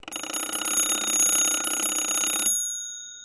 They have no chime because they were meant to be put in a bedroom. They do, however, have a very LOUD alarm mechanism that is built-in. It rings on a cast-iron bell.